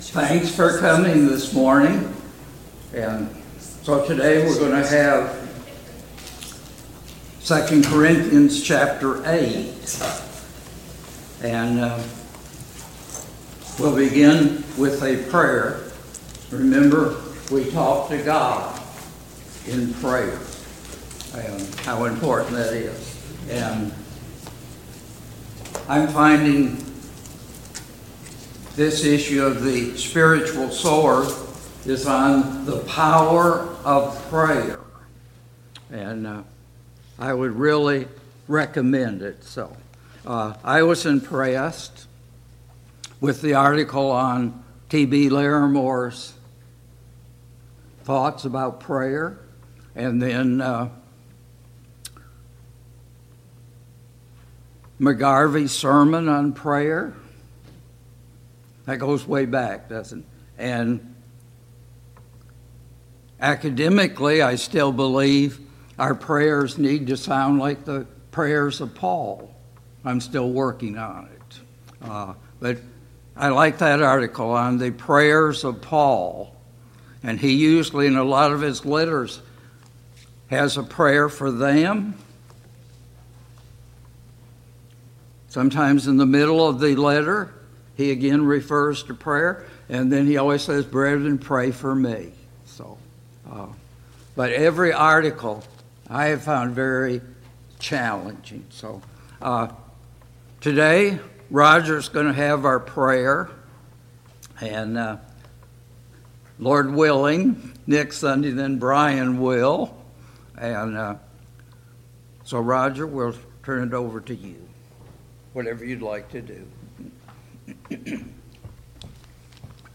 A Study of 2 Corinthians Passage: 2 Corinthians 8 Service Type: Sunday Morning Bible Class « 16.